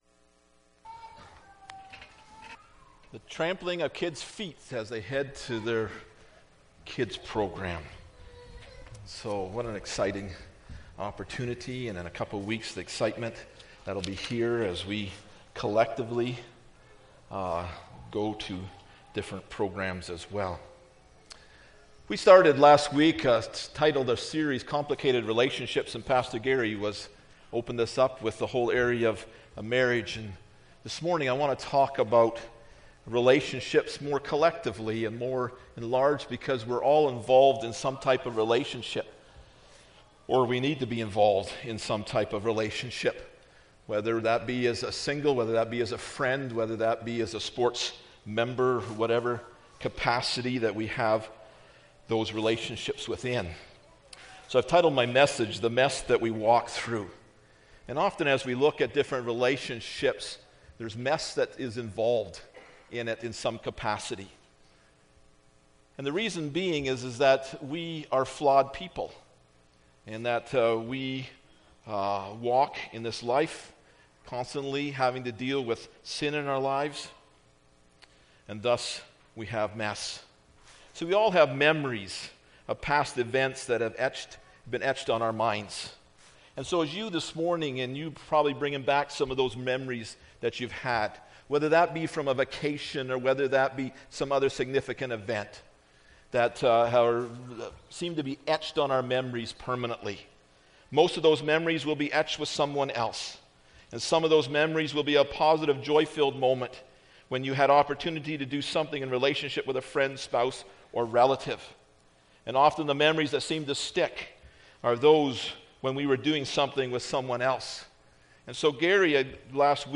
Matthew 7:3-5 Service Type: Sunday Morning Bible Text